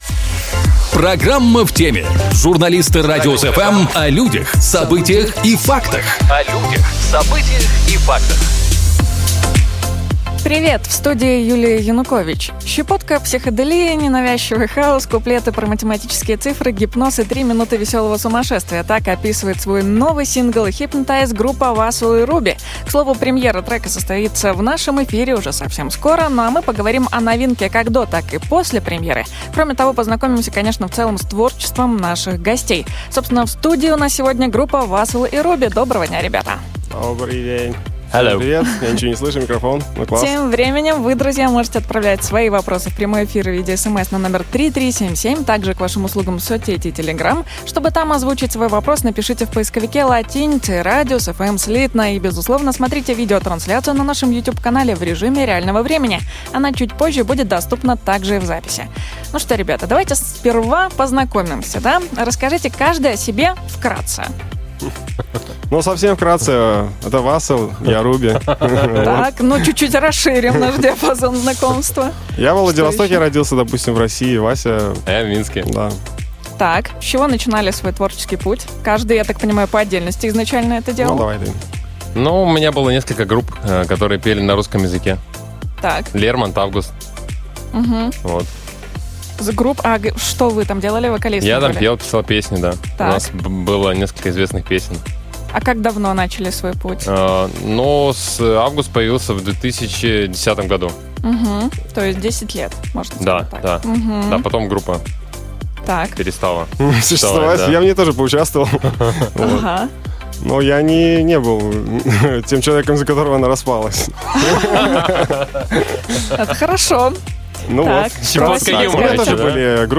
Щепотка психоделии, ненавязчивый хаус, куплеты про математические цифры, гипноз и три минуты веселого сумасшествия.
К слову, премьера песни состоится в нашем эфире.
В студии у нас сегодня музыкальный дуэт Vassel & Rooby.